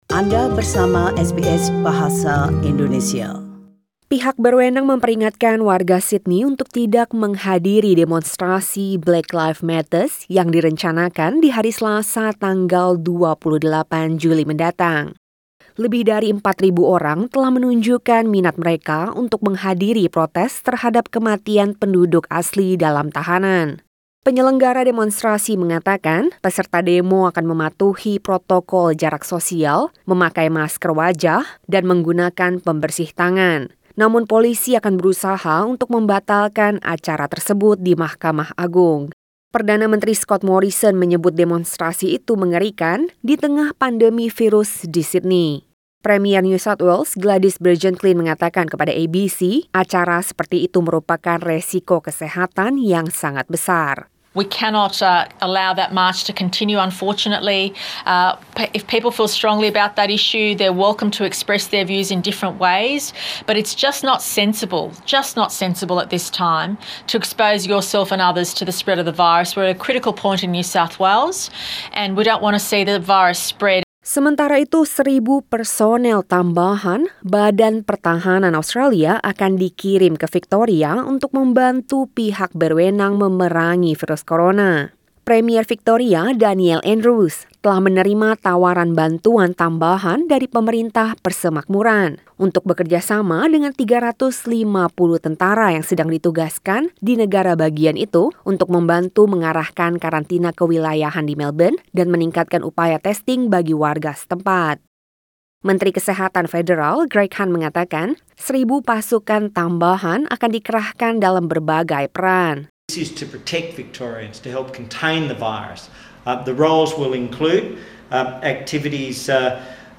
SBS Radio News in Bahasa Indonesia - 22 July 2020